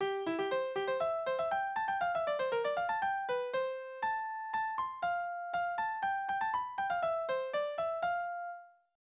Ecossaise
Tonart: C-Dur Taktart: 2/4 Tonumfang: Oktave, große Sexte Besetzung: instrumental